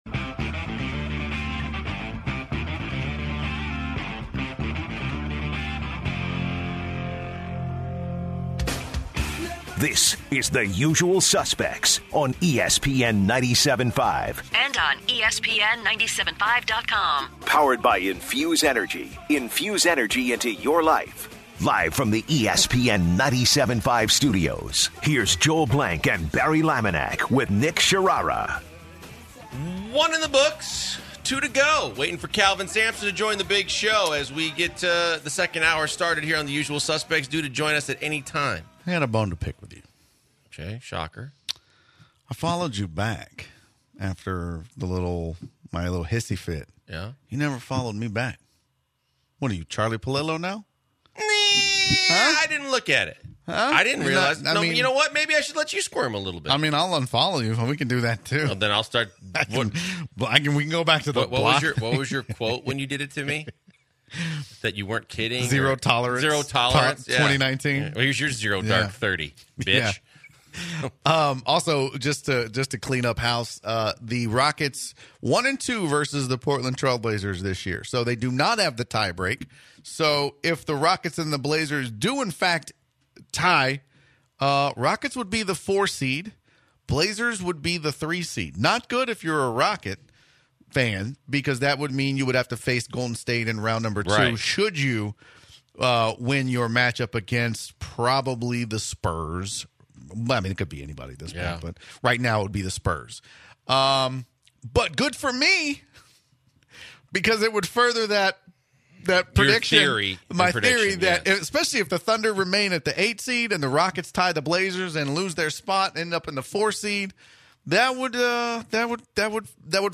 The Suspects talk some college hoops ahead of their interview with UH head coach Kelvin Sampson who joins the show to talk about the Cougars fantastic run in the NCAA tournament and their upcoming battle with Kentucky in the Sweet 16.